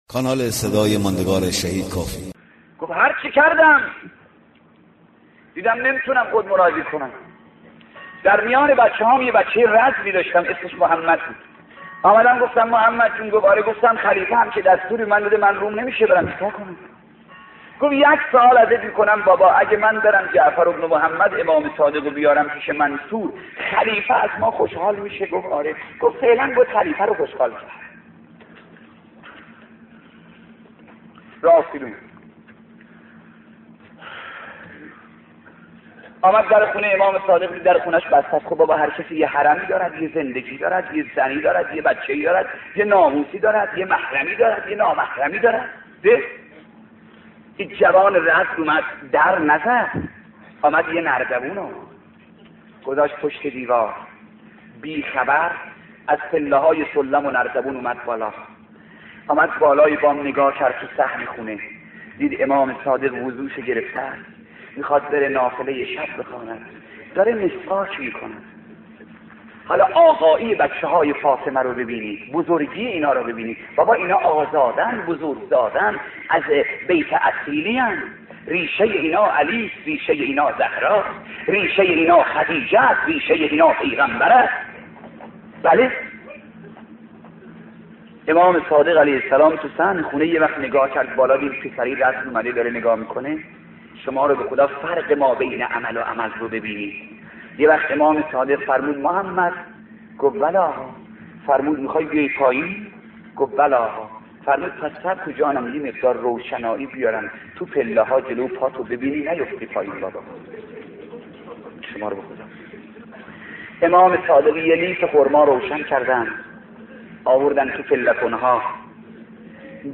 روضه امام جعفر صادق علیه السلام با صدای مرحوم شیخ احمد کافی